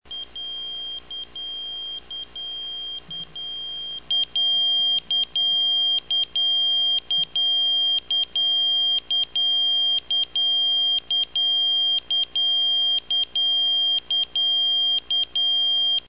sonnerie
annulation.mp3